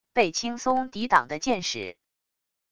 被轻松抵挡的箭矢wav音频